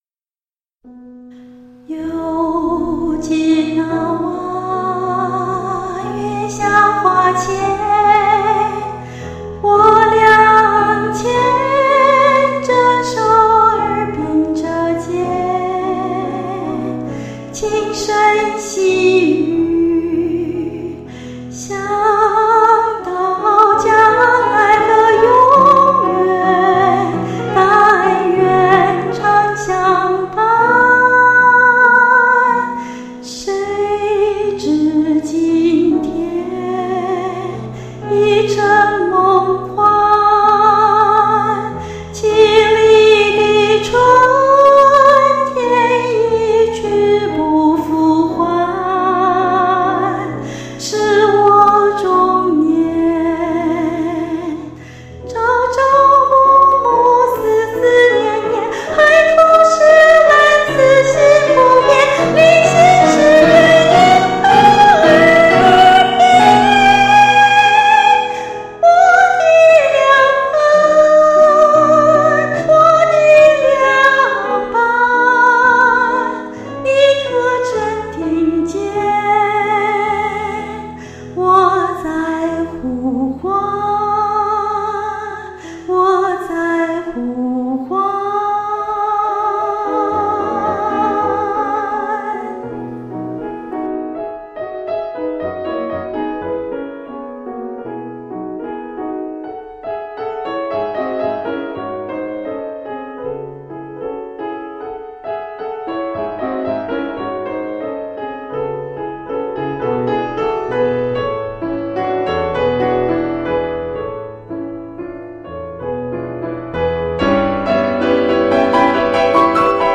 试唱 肖邦离别曲 (破音轻砸)